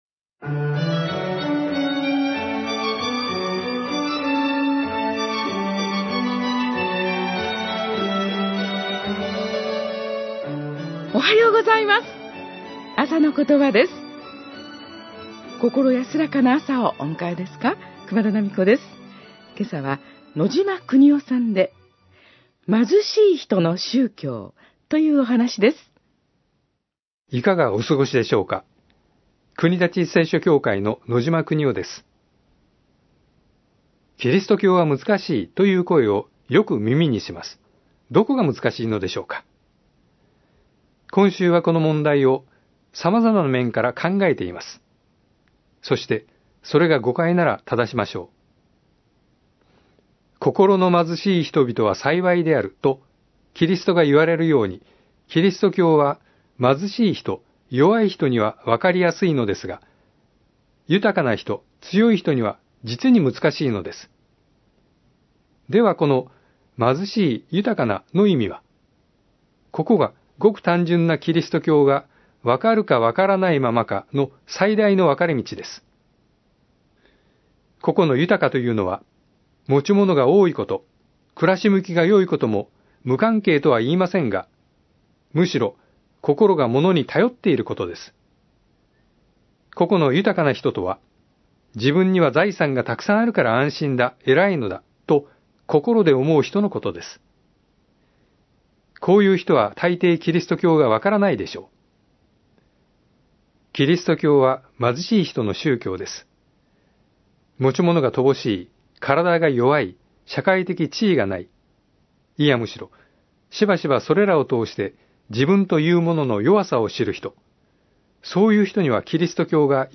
あさのことば 2016年12月1日（木）放送